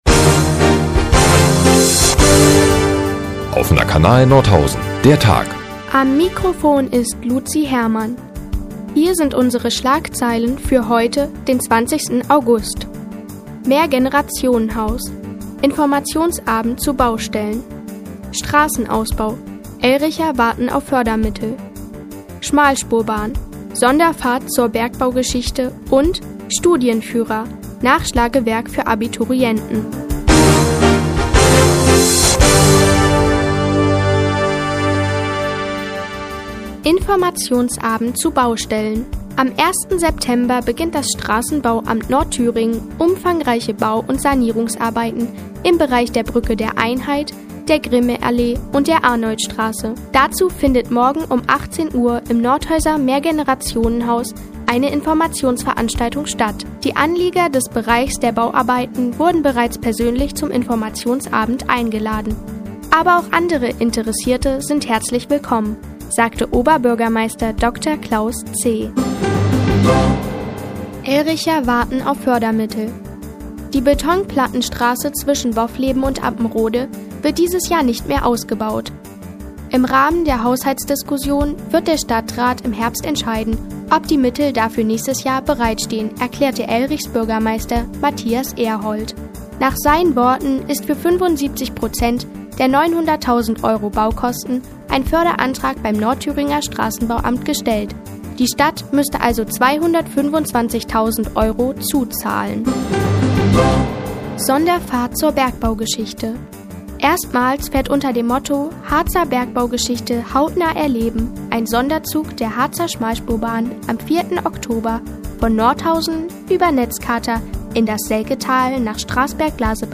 Mi, 15:30 Uhr 20.08.2014 „Der Tag auf die Ohren“ OKN (Foto: OKN) Seit Jahren kooperieren die Nordthüringer Online-Zeitungen und der Offene Kanal Nordhausen. Die tägliche Nachrichtensendung des OKN ist jetzt hier zu hören.